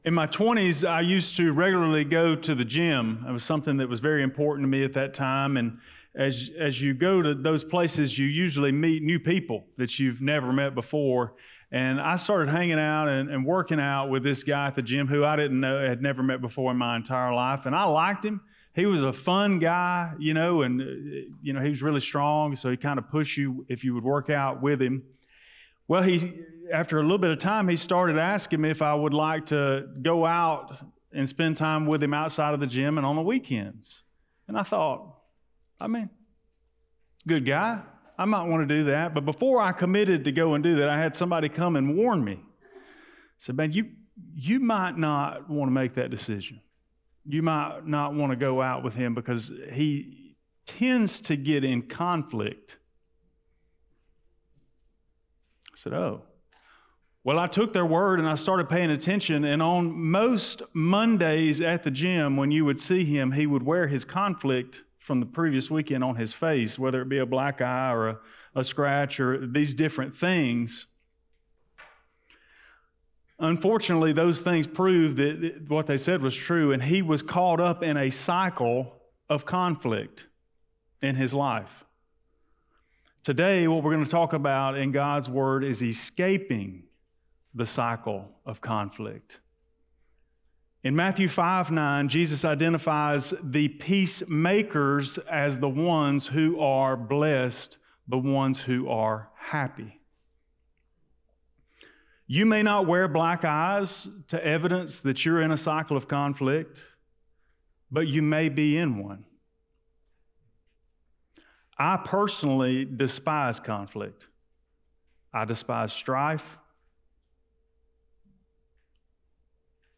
Blount Springs Baptist Church Sermons Escaping the Cycle of Conflict Jun 02 2025 | 00:33:04 1x 00:00 / 00:33:04 Subscribe Share Apple Podcasts Spotify Overcast RSS Feed Share Link Embed